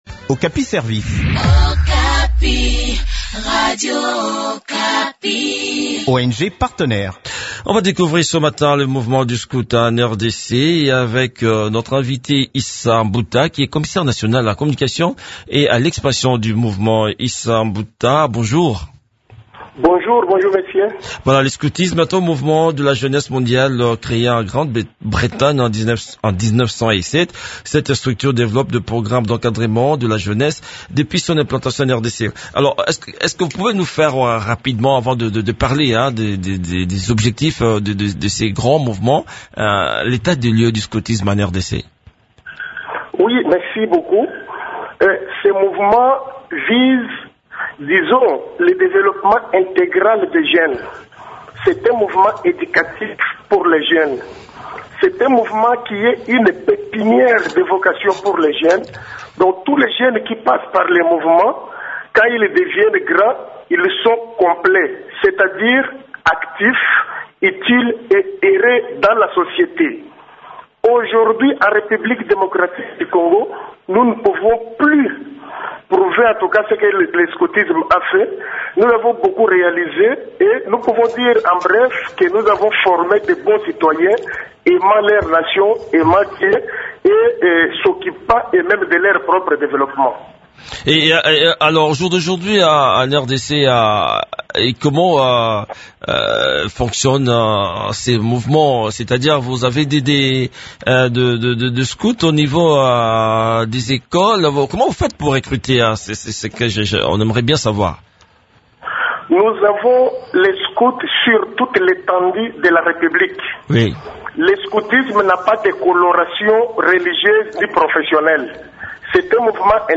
entretien